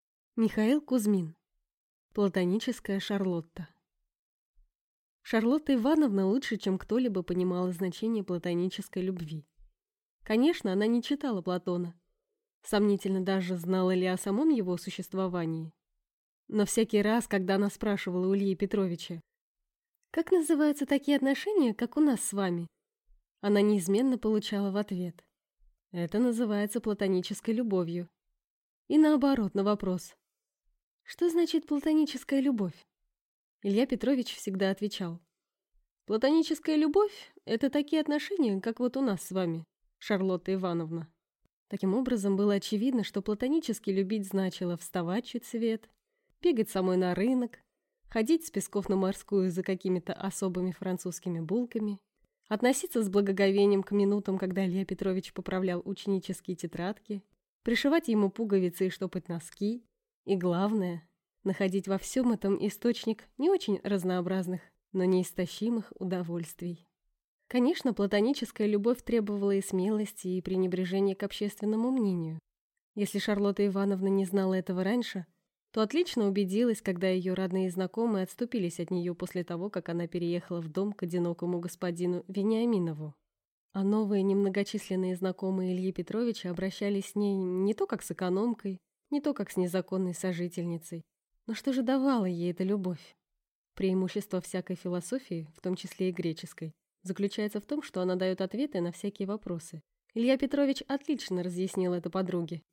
Аудиокнига Платоническая Шарлотта | Библиотека аудиокниг